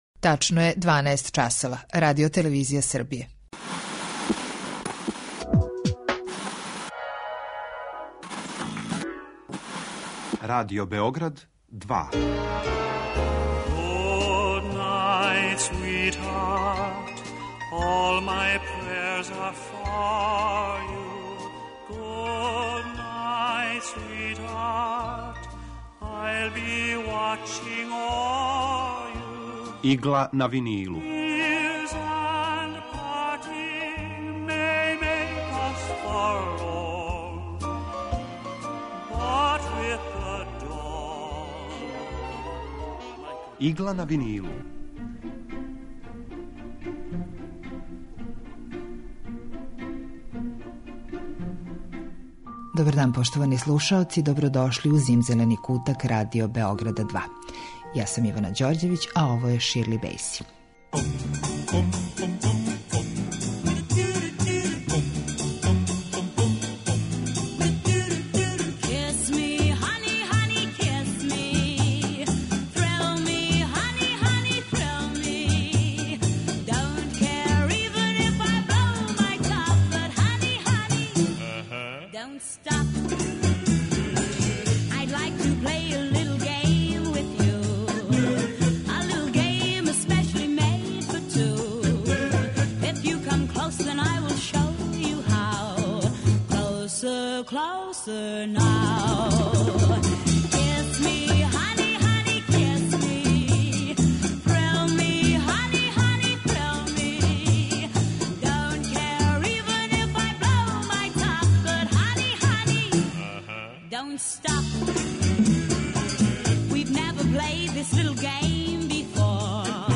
Евергрин музика